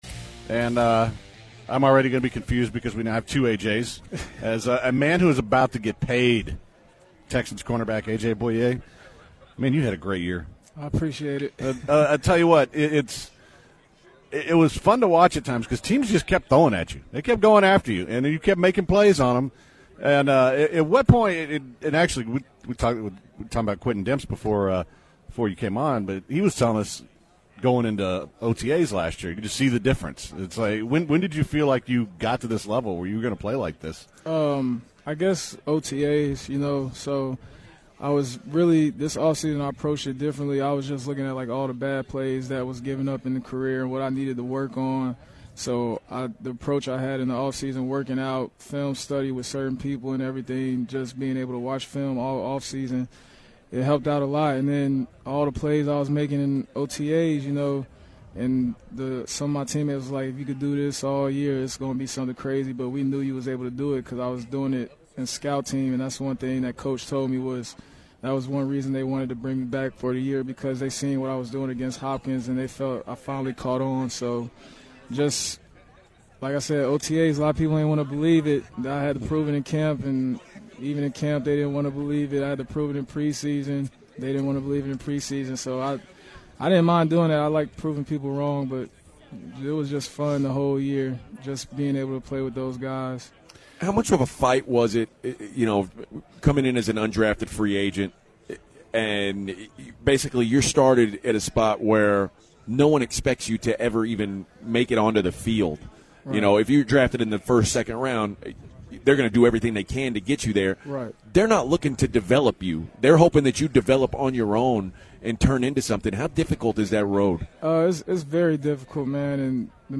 02/01/2017 A.J. Bouye Interview